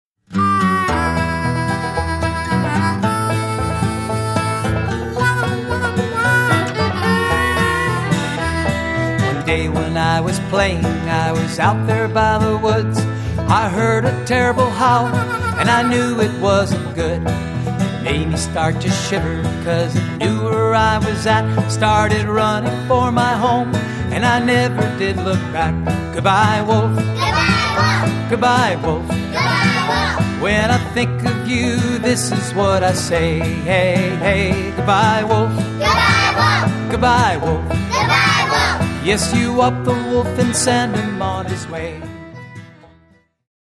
--folk-bluegrass music